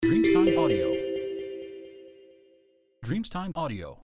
Carillon di multimedia di notifica del messaggio
• SFX